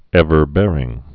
(ĕvər-bârĭng)